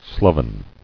[slov·en]